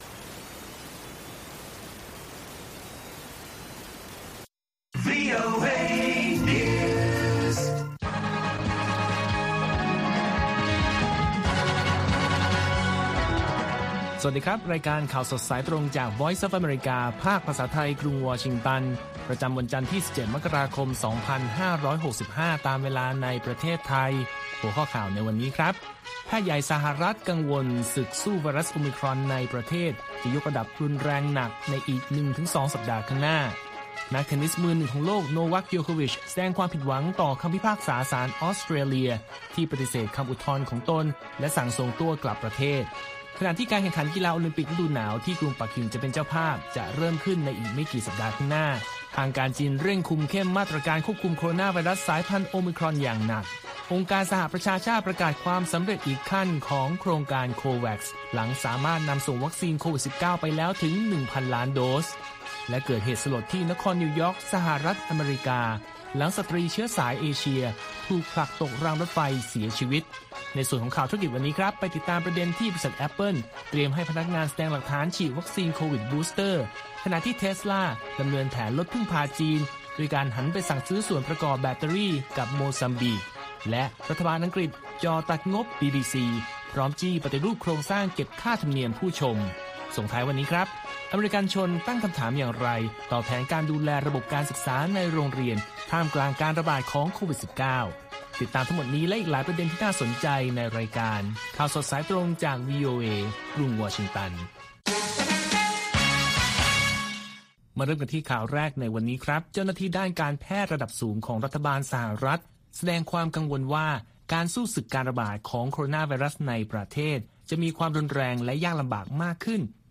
ข่าวสดสายตรงจากวีโอเอ ภาคภาษาไทย ประจำวันจันทร์ที่ 17 กันยายน 2565 ตามเวลาประเทศไทย